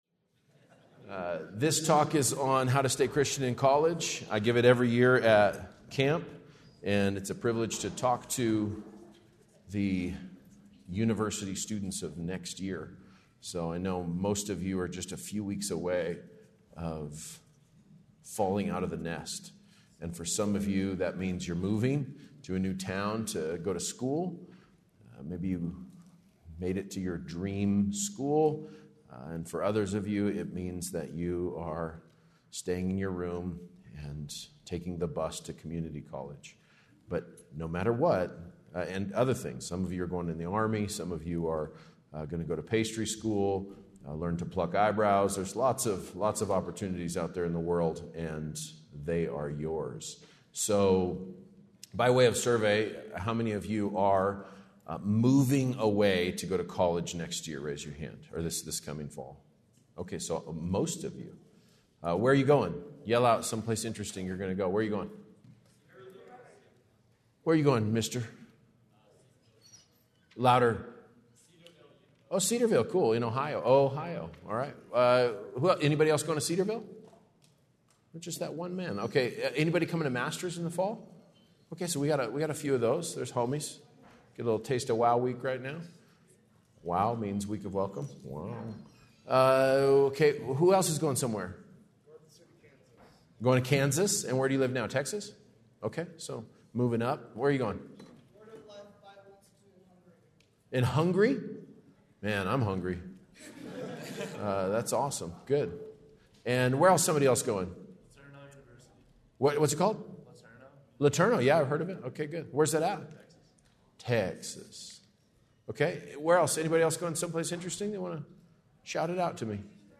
Breakout Session: Following Christ in College Matters (Seniors)